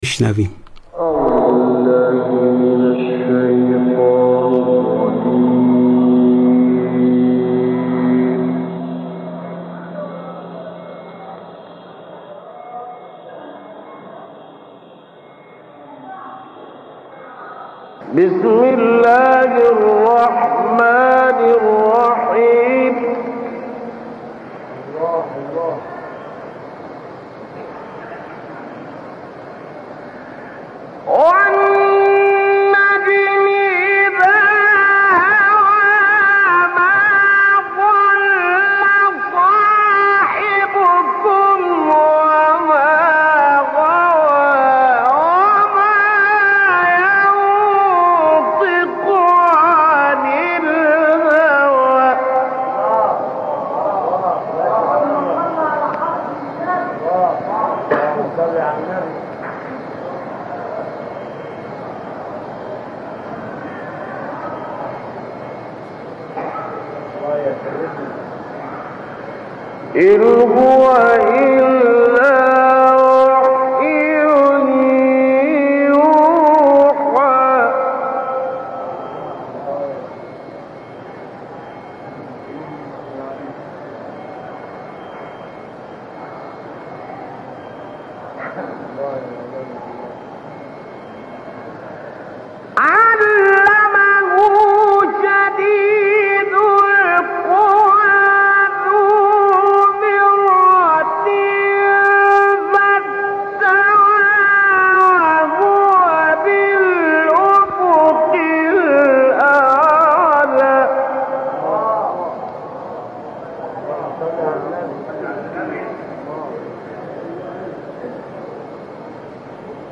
در «وَهُوَ بِالْأُفُقِ الْأَعْلَى» مقام کرد را اجرا می‌کند.